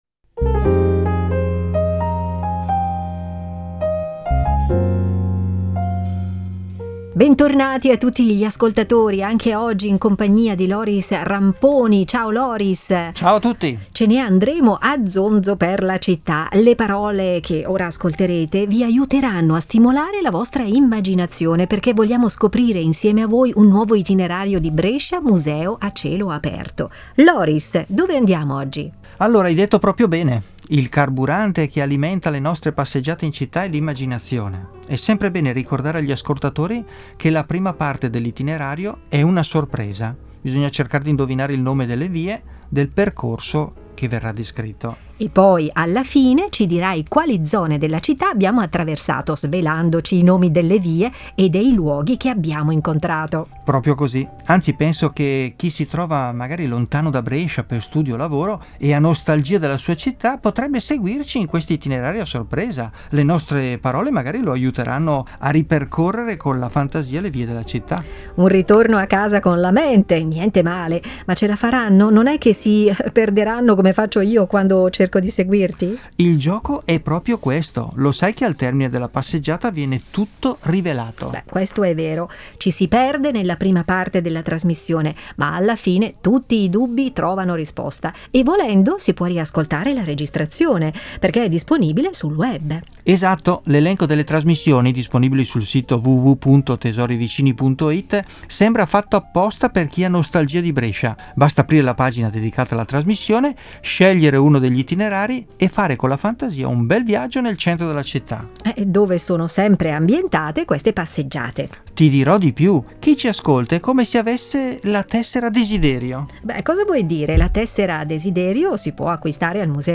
audio-guida e itinerari per passeggiare tra le vie di Brescia